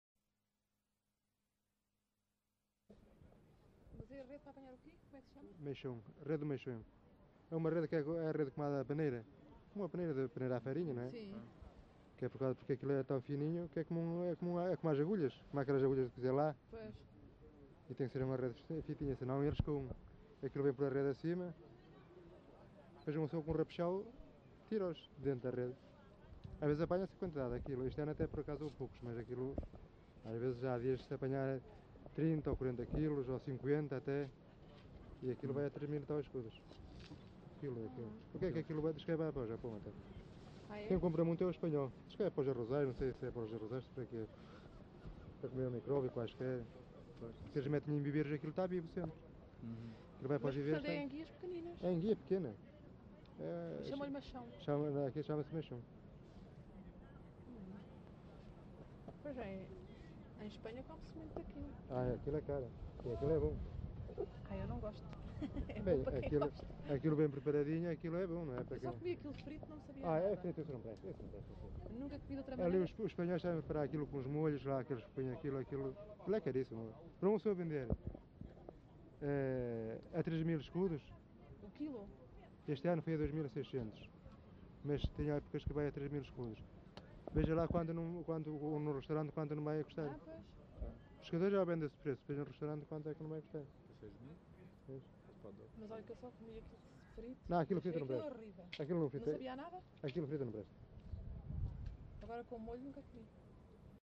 LocalidadeVila Praia de Âncora (Caminha, Viana do Castelo)
Informante(s) Agrícola